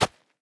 DIRT_3.ogg